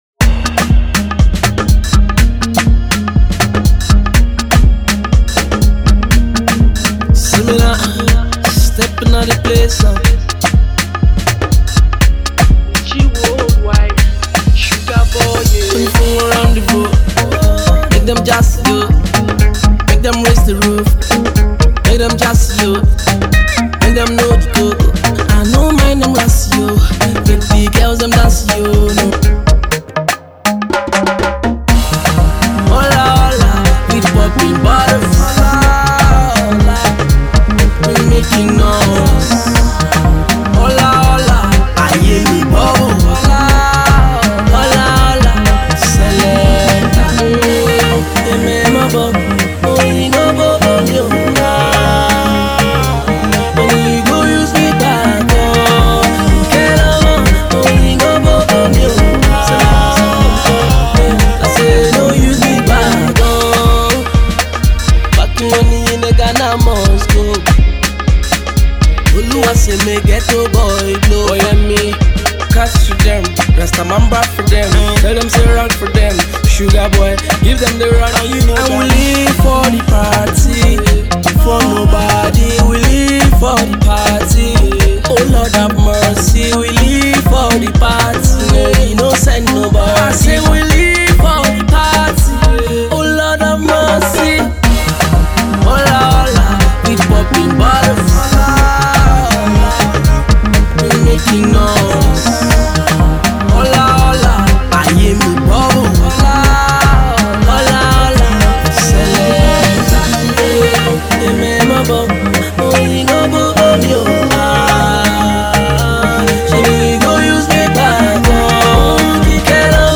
mid-tempo afrobeat reggae tune